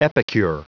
Prononciation du mot epicure en anglais (fichier audio)
Prononciation du mot : epicure